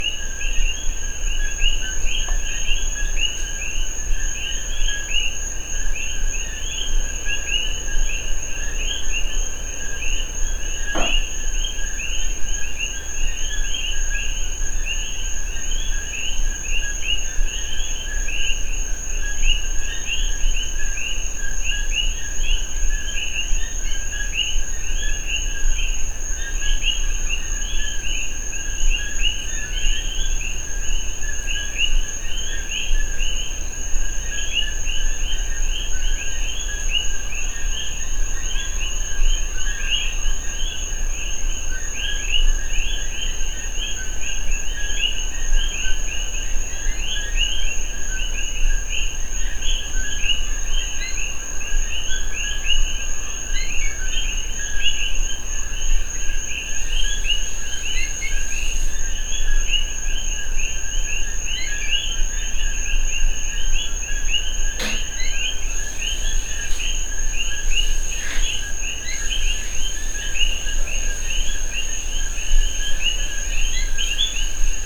The lanai is screened in, so it’s like we are sitting outside, and a million tree frogs are serenading us!  It’s like the koki frogs in Puerto Rico.  Most of them are saying koki (though I have no idea if they’re the same frogs) but lots of others have more complicated melodies to add to the cacophony and the music is glorious!
Koki-Frogs-etc.mp3